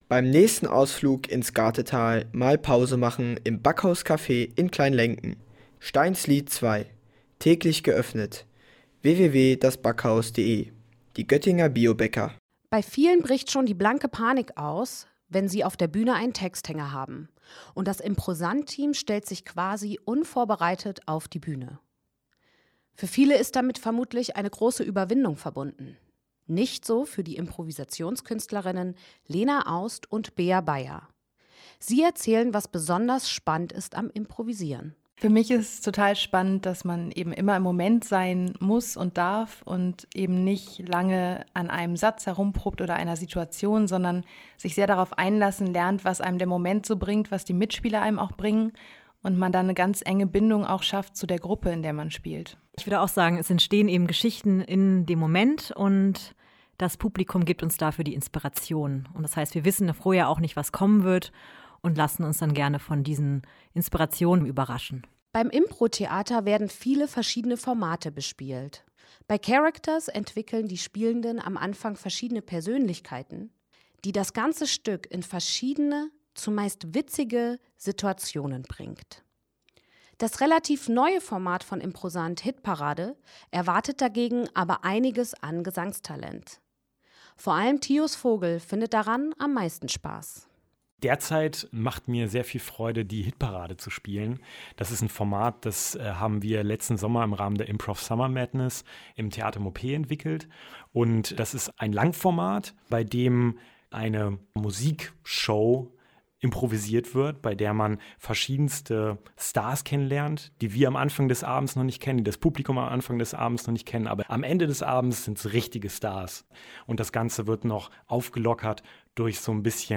Das Improsant-Team beim Improvisieren im StadtRadio-Studio